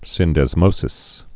(sĭndĕz-mōsĭs, -dĕs-)